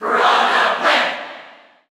Category: Crowd cheers (SSBU) You cannot overwrite this file.
Piranha_Plant_Cheer_English_SSBU.ogg